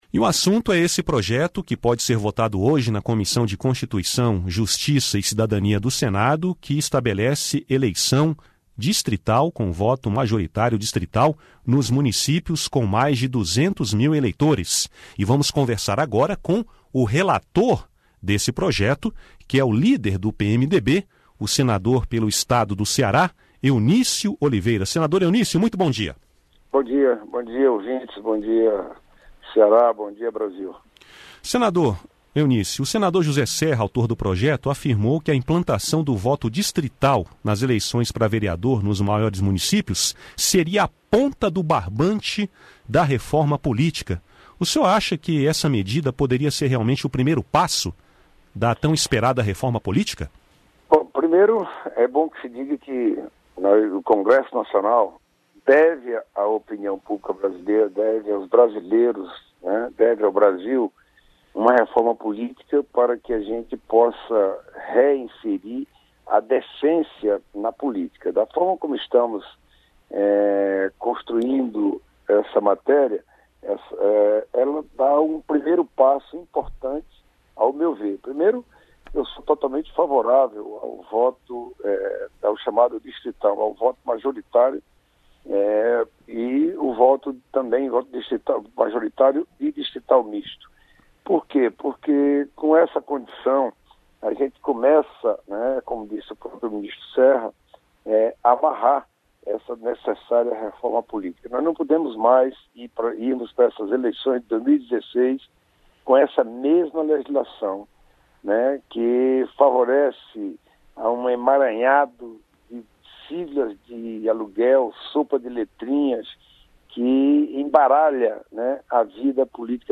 Em entrevista à Rádio Senado, o relator da matéria, senador Eunício Oliveira (PMDB-CE) diz que o Congresso Nacional deve à população uma Reforma Política que reinsira a decência na política brasileira. Eunício Oliveira se diz favorável ao modelo de voto distrital proposto no projeto.